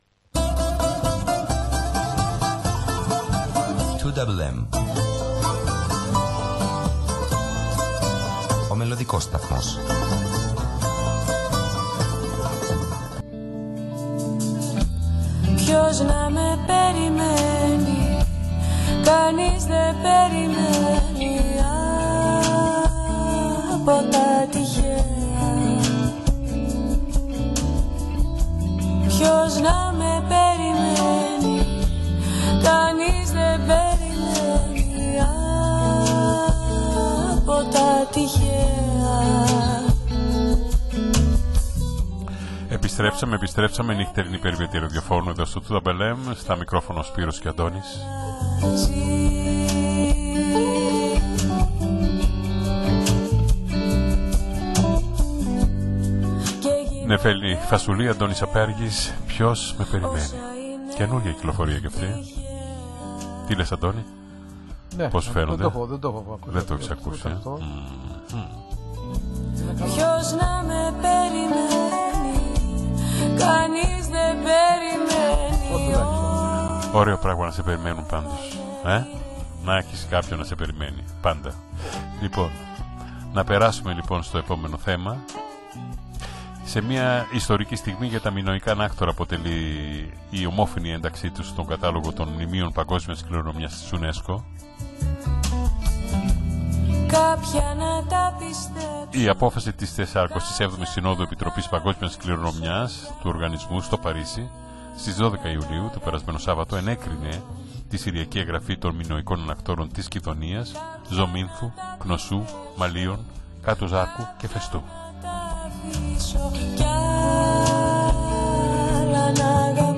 μίλησε ζωντανά
του ελληνόφωνου ραδιοσταθμού